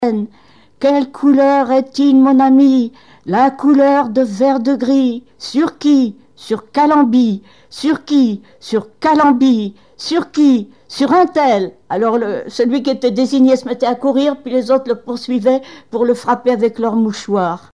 Mémoires et Patrimoines vivants - RaddO est une base de données d'archives iconographiques et sonores.
jeu collectif
Témoignages et chansons
Pièce musicale inédite